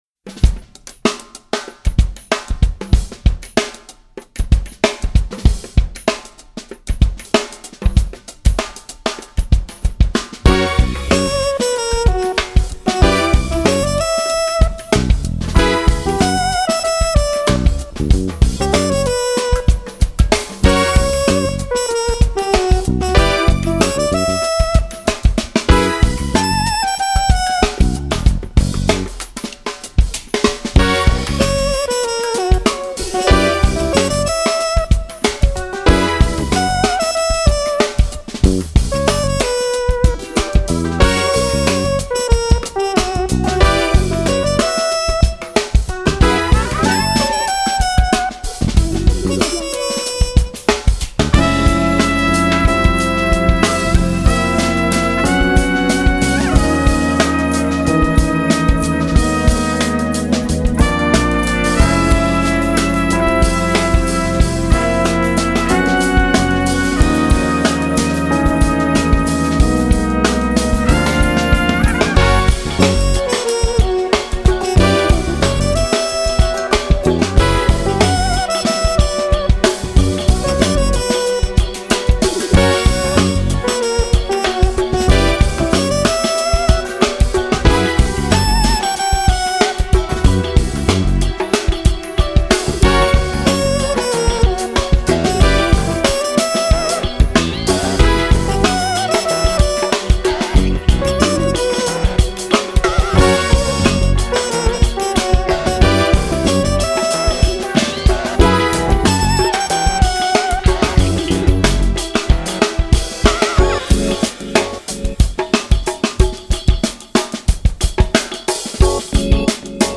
フィンランド発、スタイリッシュなクラブジャズサウンドが展開
フュージョン、クラブジャズ系ファンへオススメ。
keyboards, piano
trumpet, flugelhorn
double bass, bass guitar
drums
percussions